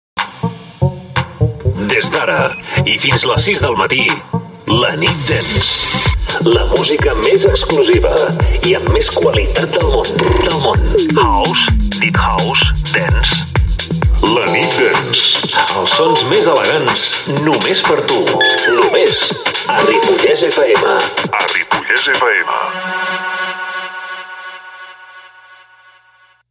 Careta del programa Gènere radiofònic Musical